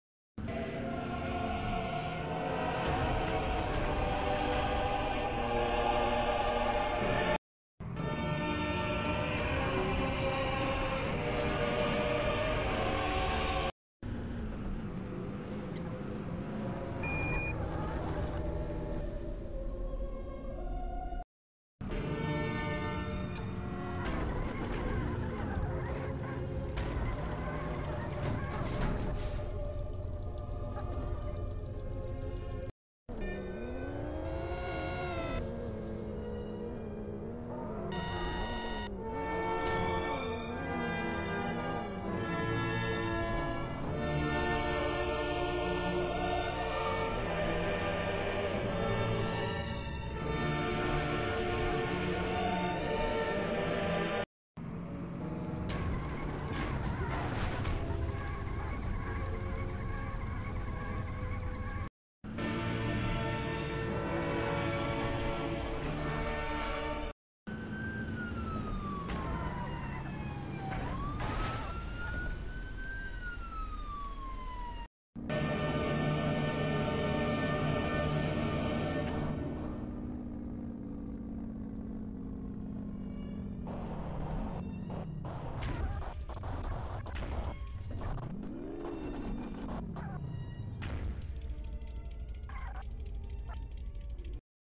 pug van SWAT van pickup truck crash